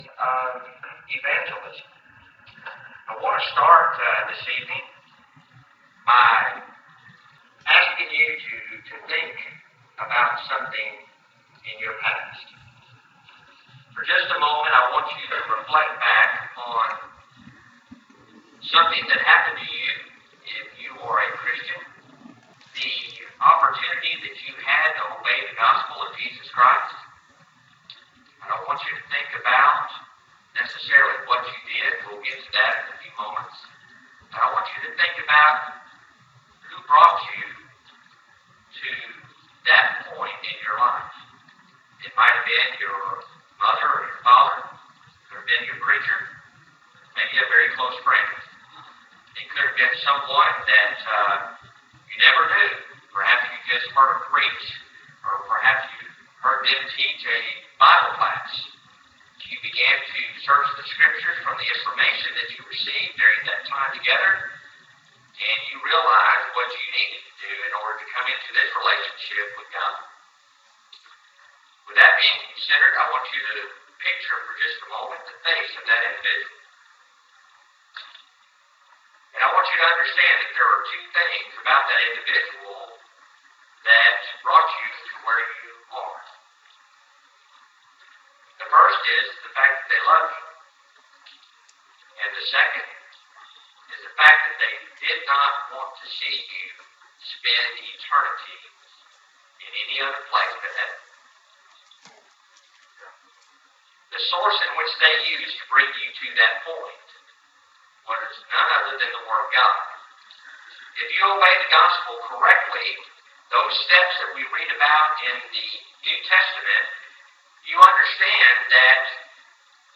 2015-Summer-Sermons-4-a.mp3